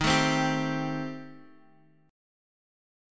D#6b5 chord